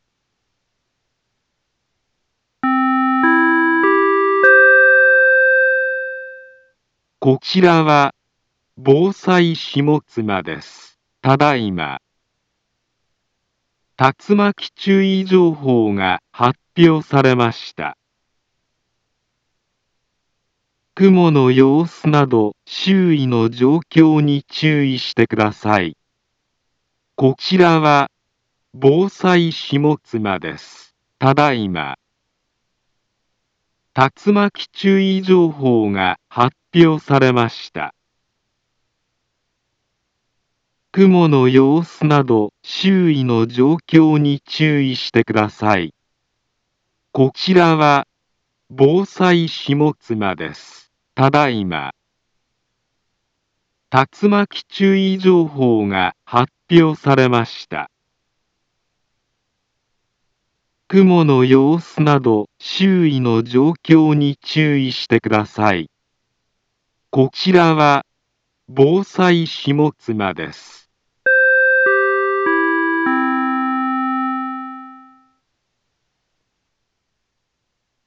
Back Home Ｊアラート情報 音声放送 再生 災害情報 カテゴリ：J-ALERT 登録日時：2024-07-27 18:24:30 インフォメーション：茨城県南部は、竜巻などの激しい突風が発生しやすい気象状況になっています。